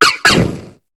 Cri de Feunnec dans Pokémon HOME.